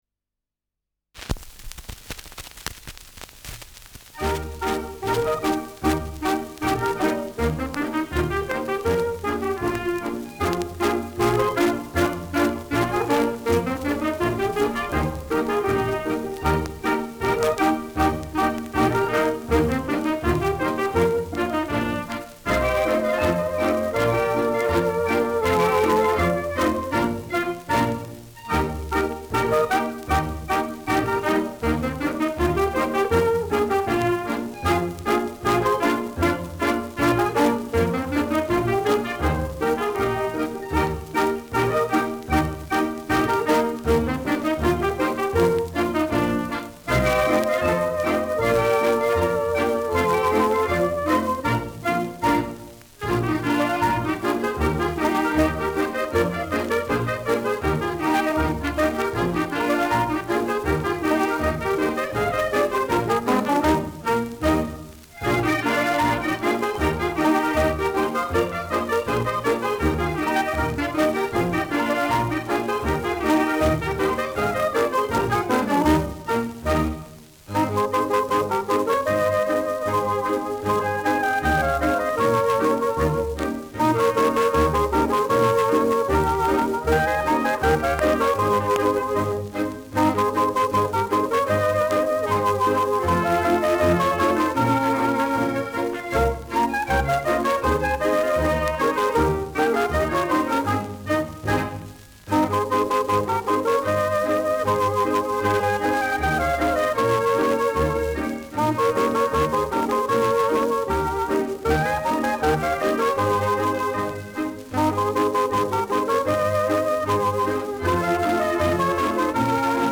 Schellackplatte
Ländlerkapelle* FVS-00018